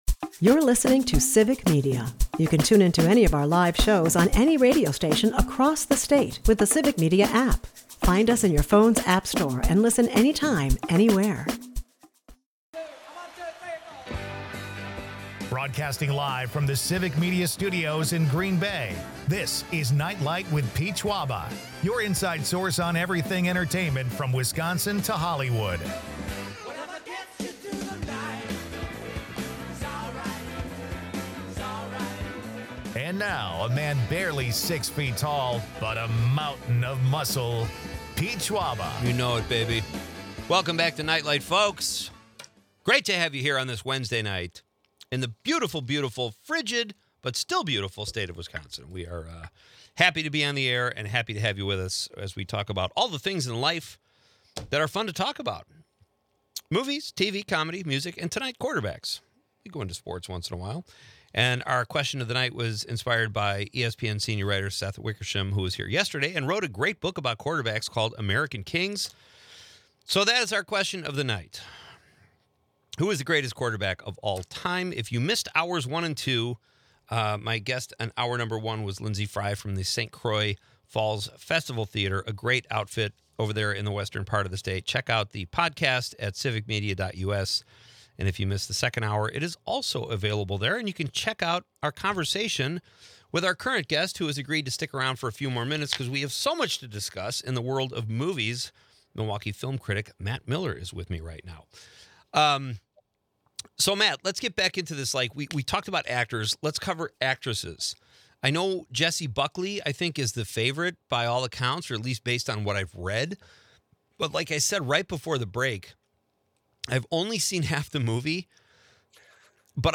a lively discussion with guests about movies, sports, and unique hobbies